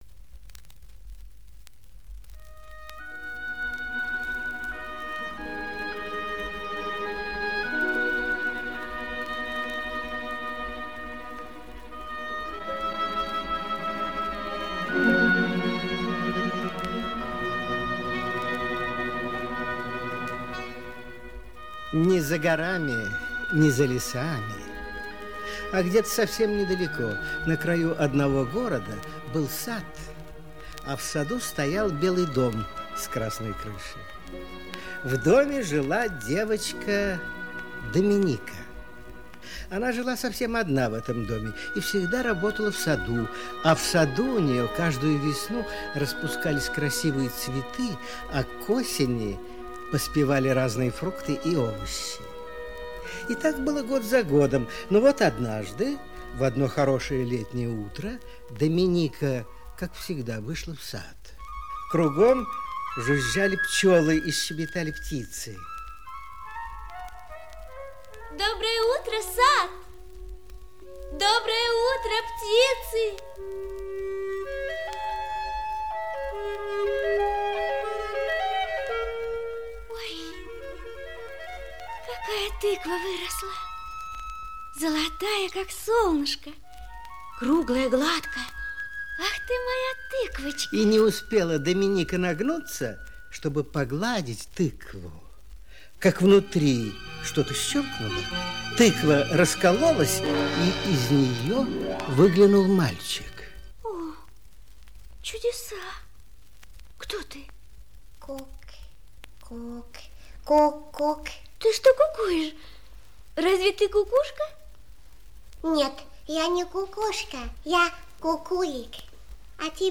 Братец из тыквы - аудиосказка Дюричковой - слушать онлайн | Мишкины книжки